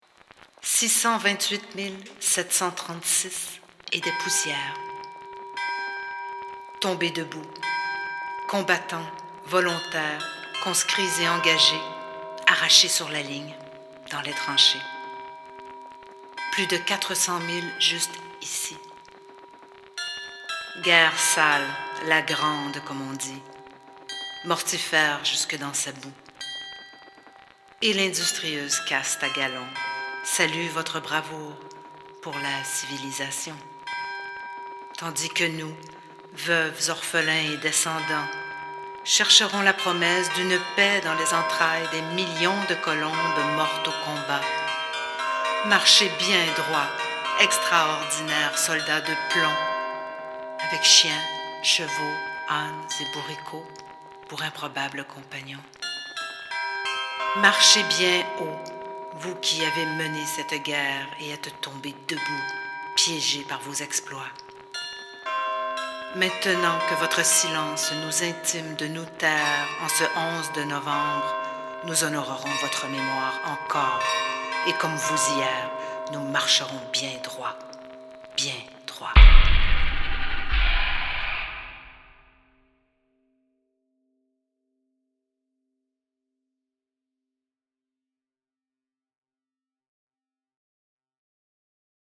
Carillon